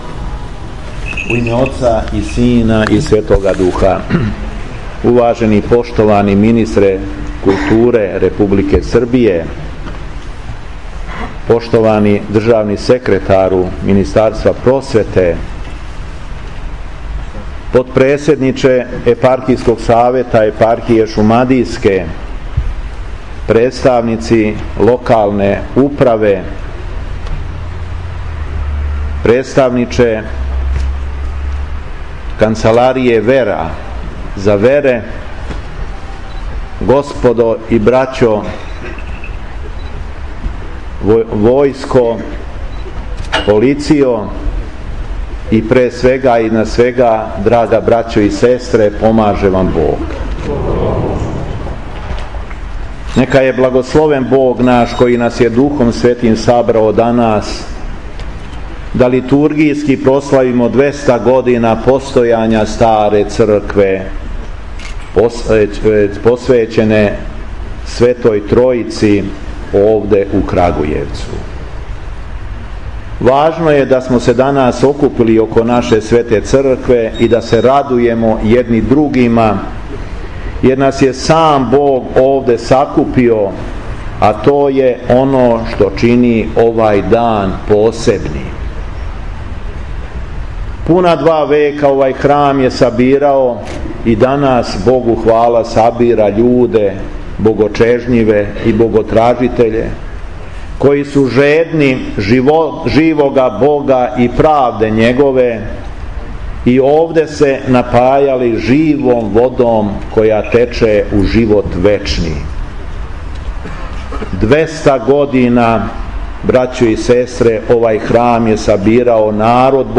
СВЕЧАНА ПРОСЛАВА ДВЕСТОГОДИШЊИЦЕ СТАРЕ ЦРКВЕ У КРАГУЈЕВЦУ - Епархија Шумадијска
Духовна поука Епископа шумадијског Г. Јована